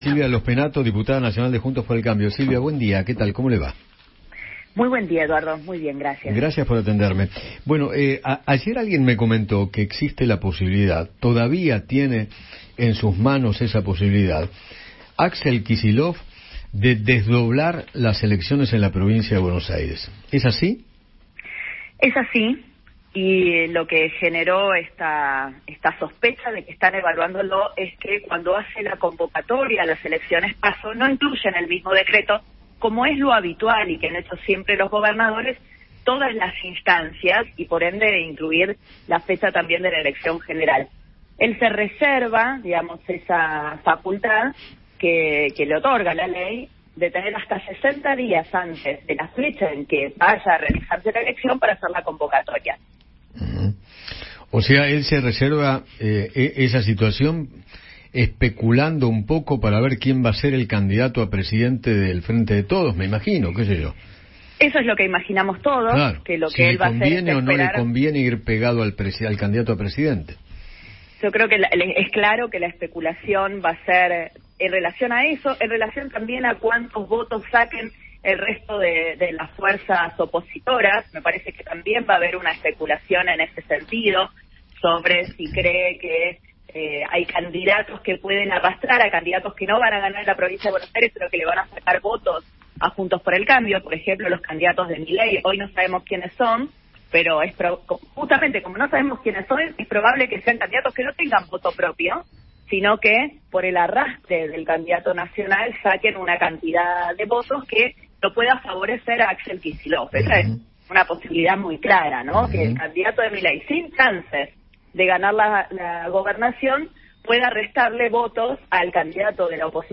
Silvia Lospennato, diputada nacional de Juntos por el Cambio, conversó con Eduardo Feinmann sobre la posibilidad de que haya un desdoblamiento de las elecciones generales en la provincia de Buenos Aires.